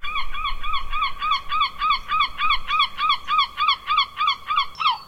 sounds_seagull_01.ogg